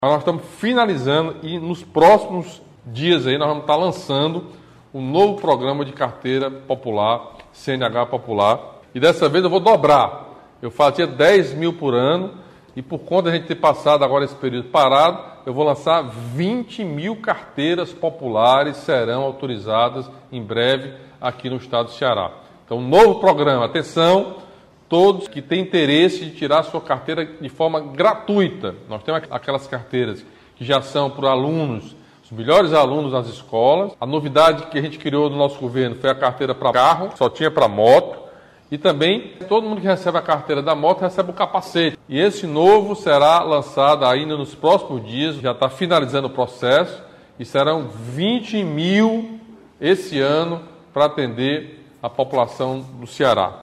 Durante a live, o governador fez críticas ao mecanismo de produção de notícias falsas que desrespeita agentes públicos e desinforma a população.